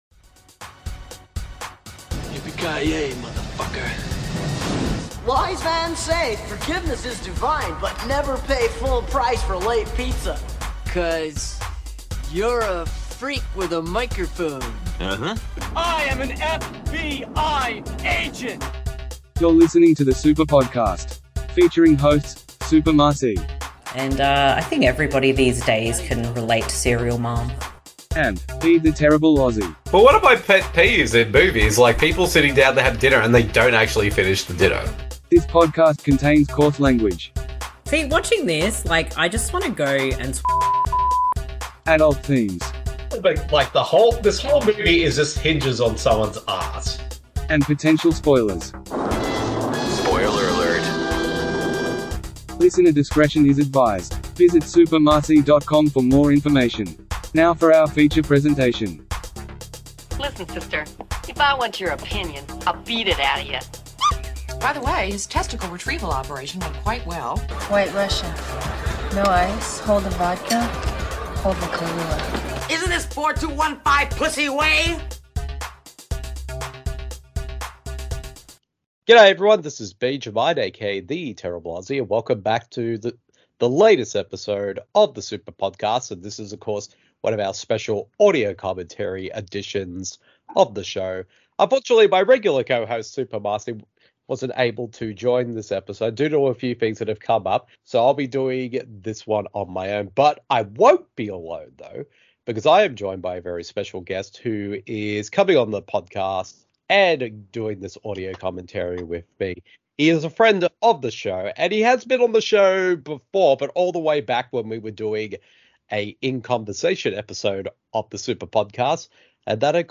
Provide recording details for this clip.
You simply need to grab a copy of the film, and sync up the podcast audio with the film.